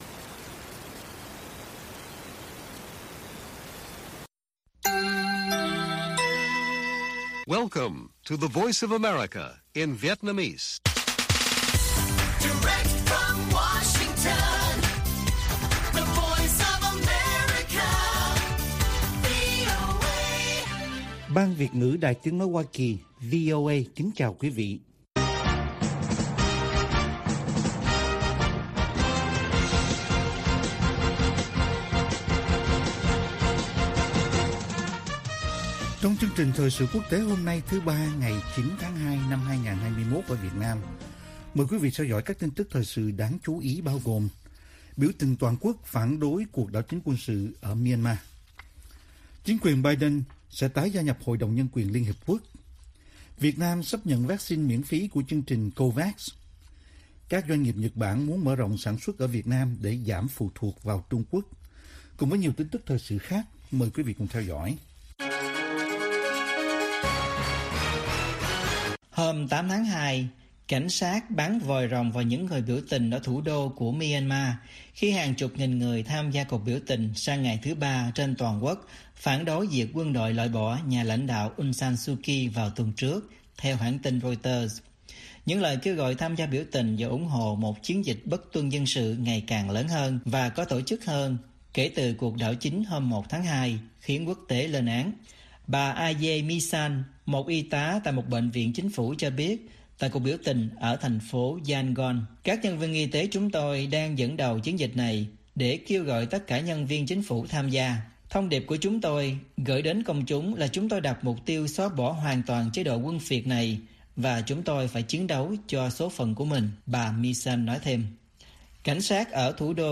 Bản tin VOA ngày 9/2/2021